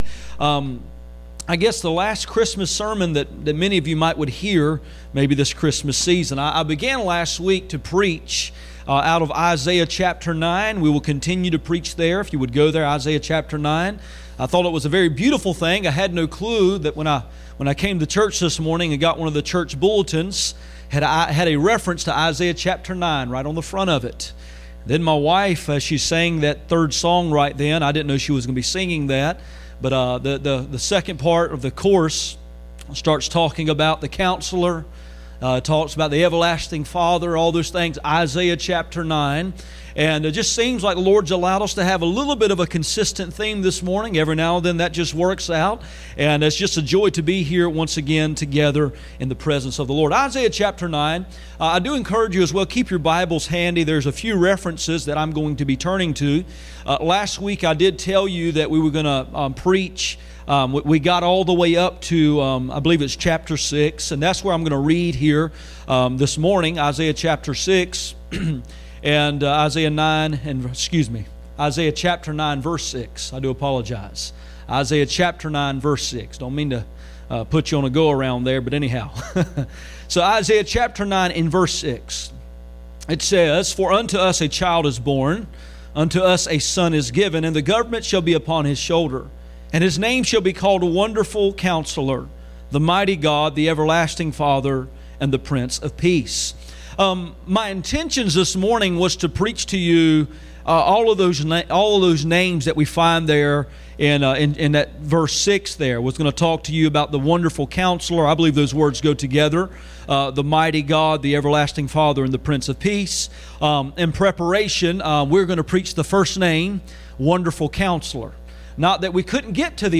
Isaiah 9:6 Service Type: Sunday Morning %todo_render% « Worshiping like a Wise Man Gates